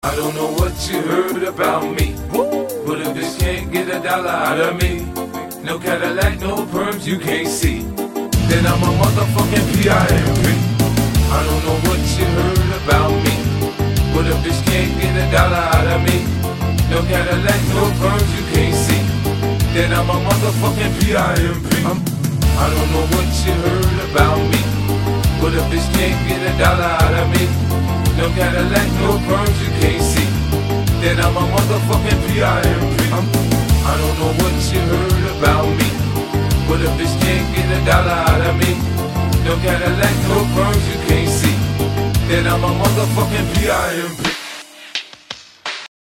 • Качество: 128, Stereo
mash up